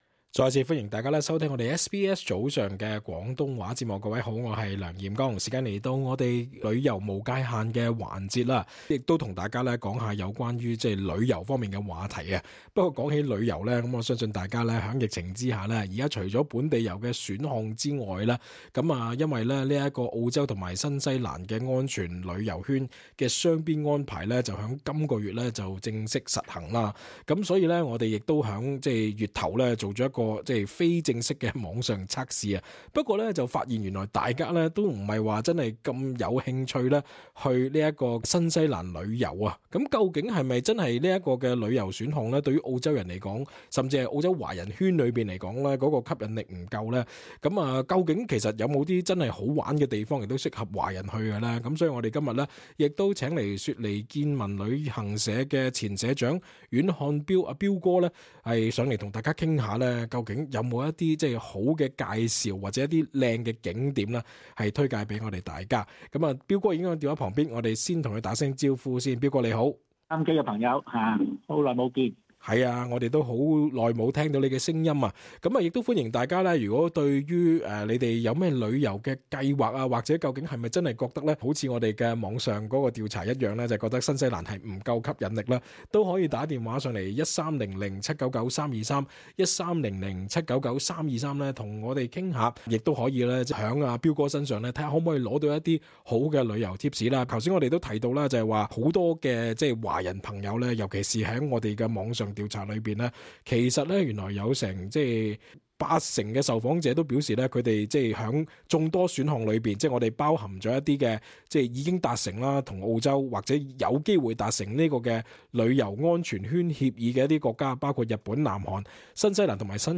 travel_talkback_podcast.mp3